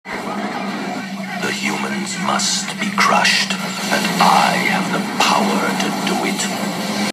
From X-Men: The Animated Series.